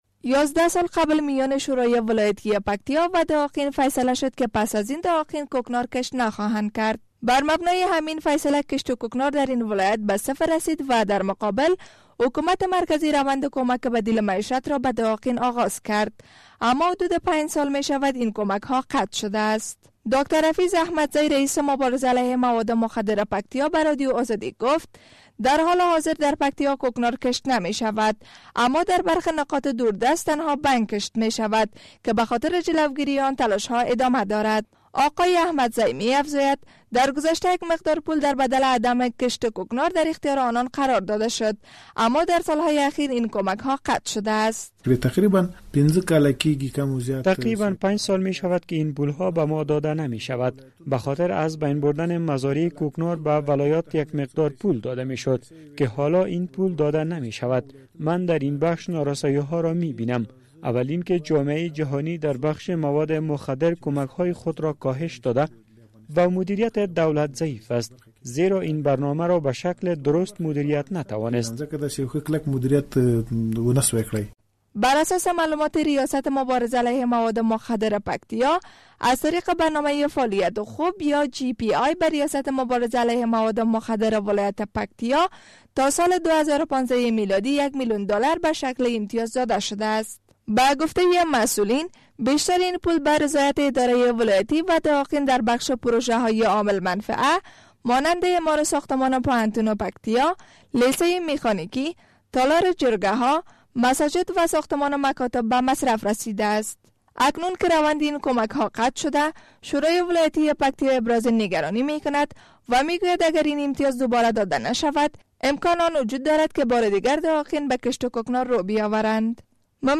برای شنیدن این گزارش روی لینک زیر کلیک نماید!!